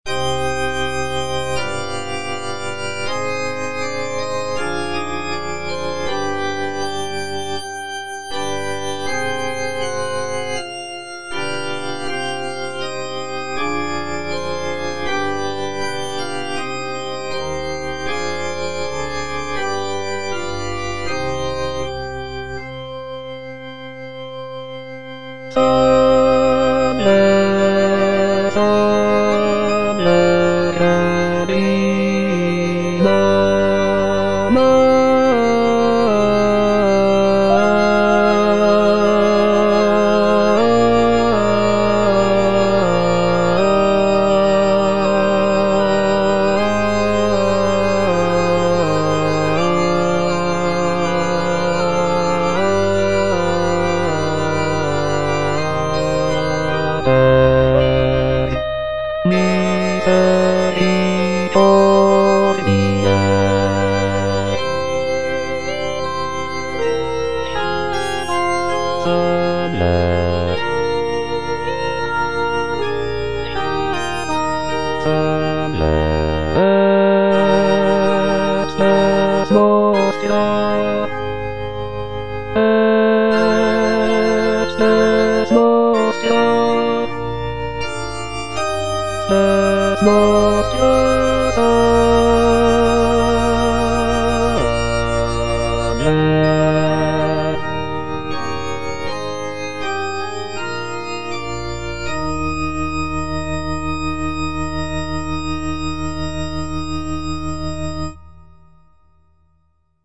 Choralplayer playing Salve Regina in C minor by G.B. Pergolesi based on the edition IMSLP #127588 (Breitkopf & Härtel, 15657)
G.B. PERGOLESI - SALVE REGINA IN C MINOR Salve Regina - Bass (Emphasised voice and other voices) Ads stop: auto-stop